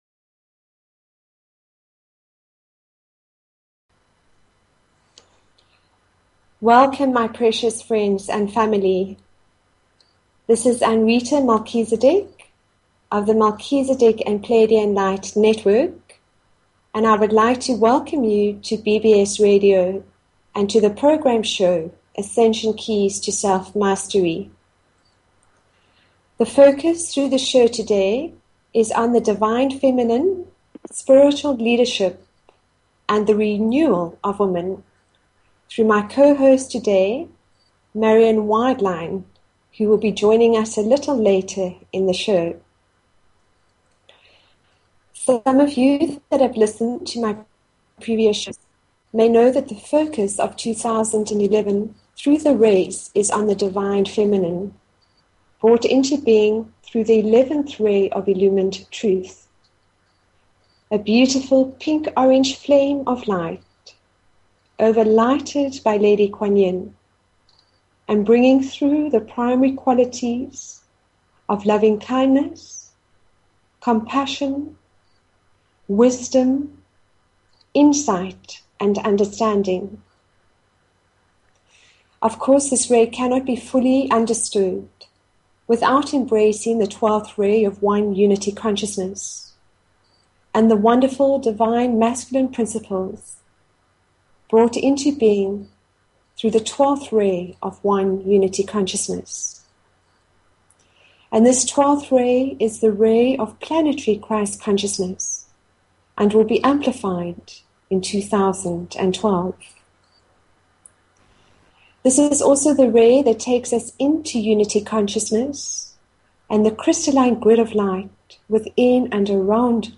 Talk Show Episode, Audio Podcast, Ascension_Keys_to_Self_Mastery and Courtesy of BBS Radio on , show guests , about , categorized as